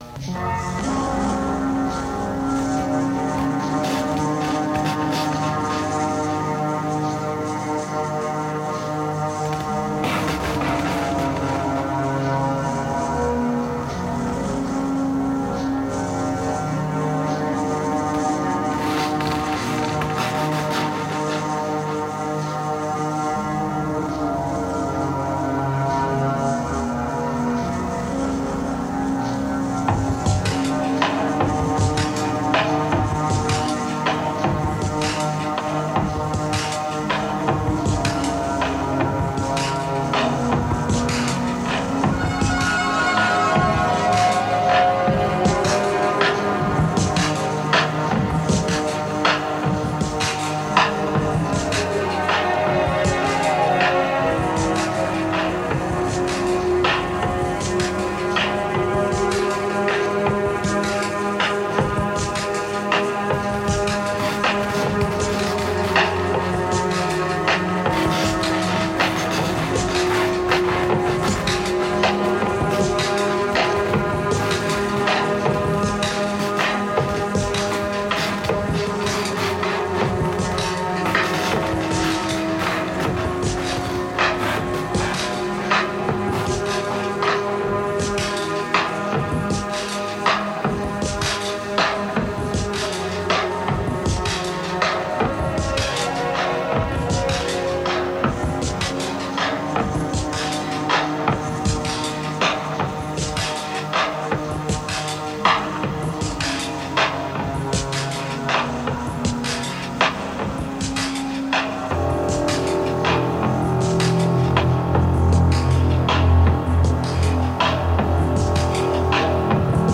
Also, here’s a little improvised jam that I did on the S4 a while back, just looping the same samples within the same pattern. At around 1:55 I bring in a drone sound which is just a short portion of a longer sustained note and it’s got some modulation on it but it’s like, a very short portion of a note just repeatedly looping which makes it drone.
It’s nothing incredible as a composition but just as a frame of reference, I’m crossfading pretty heavily on that drone note and the S4 doesn’t even break a sweat.